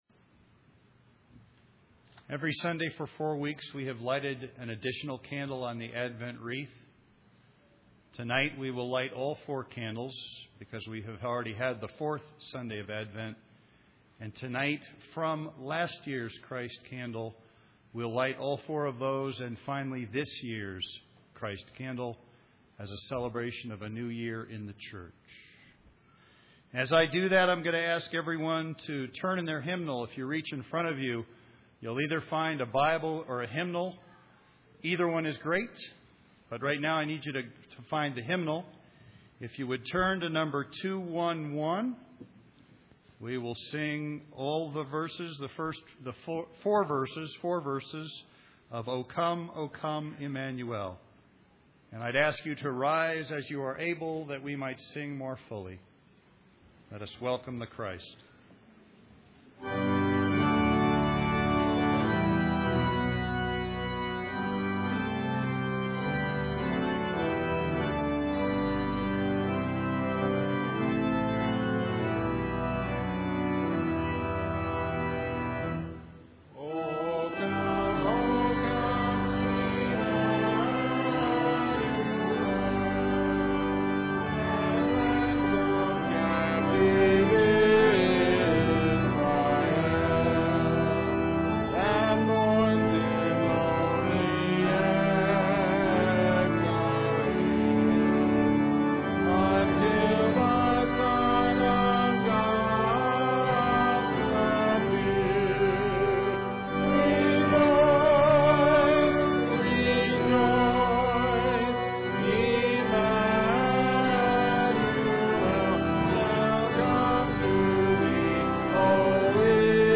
Christmas Eve Music Program & Service 2010
Hymn of Promised Coming                                                          "O Come, O Come Emmanuel"